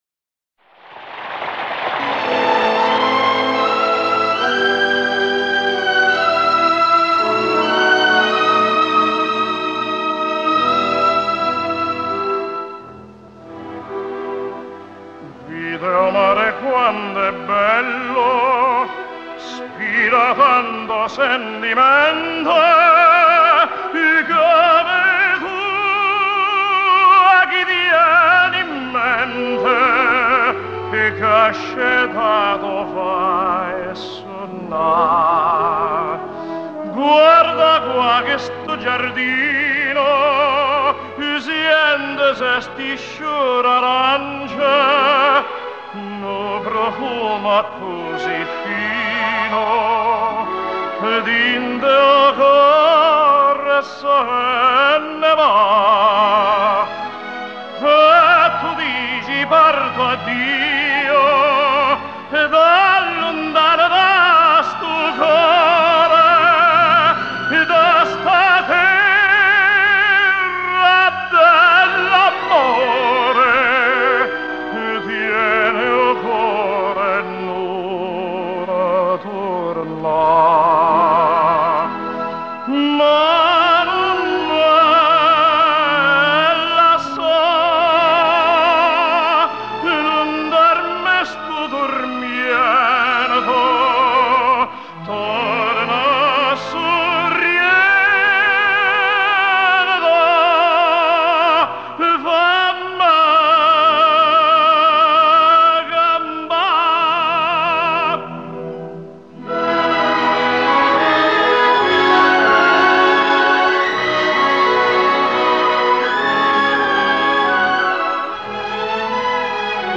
★一個久違的、熟悉的聲音
★優質男高音之最 讓我們一聽就沉醉 深深再回味 願與他的歌聲 永遠相依又相偎